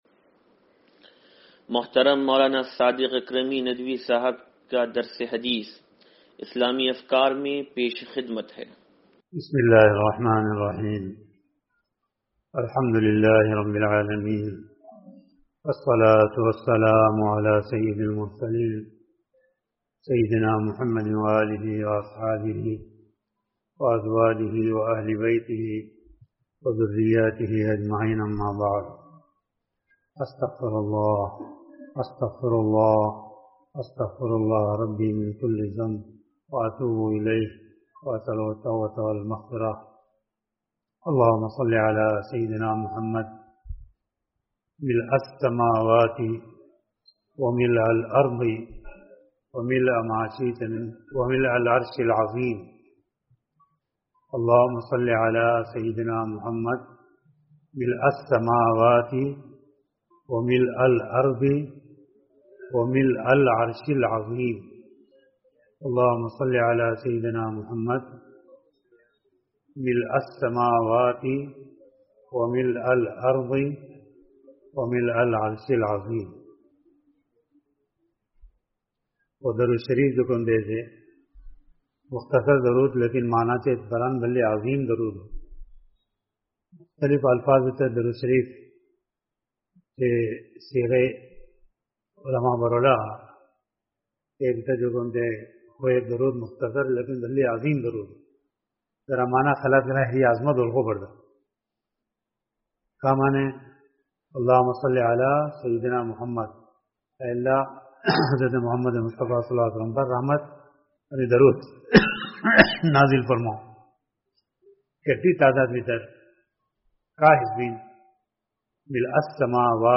درس حدیث نمبر 0741